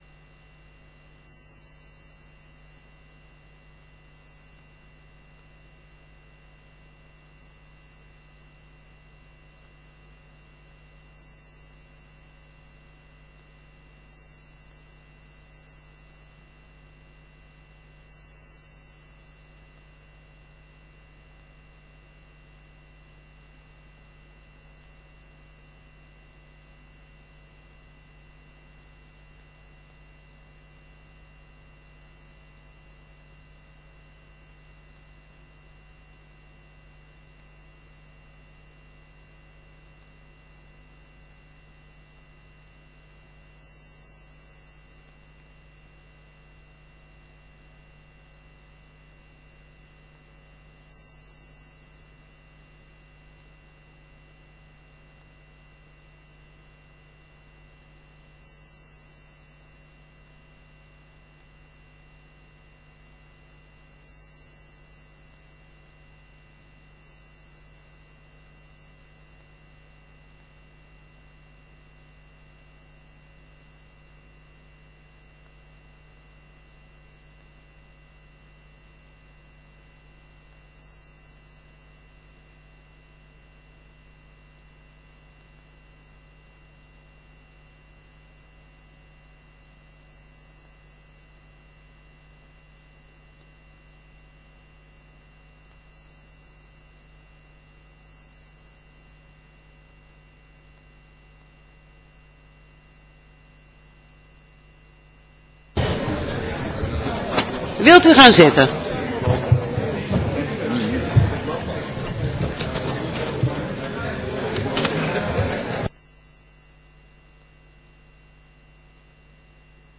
Stemmingen
Locatie: Raadzaal